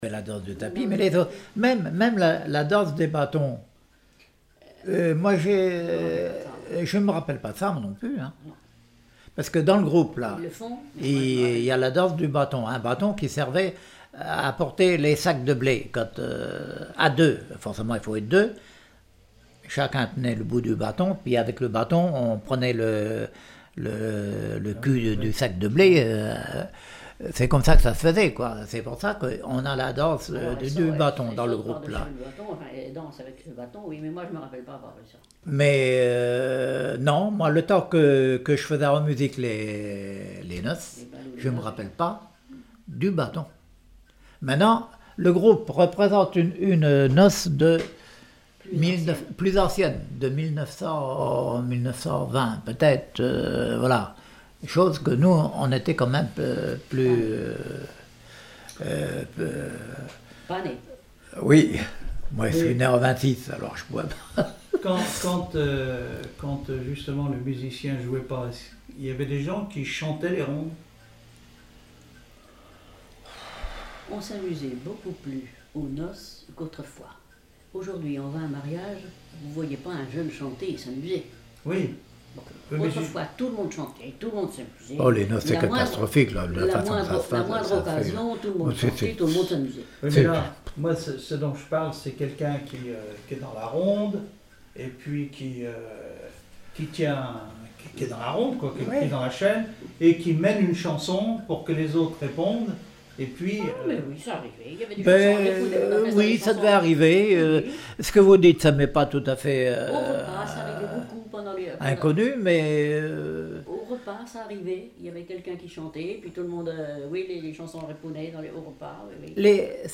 Témoignage comme joueur de clarinette
Catégorie Témoignage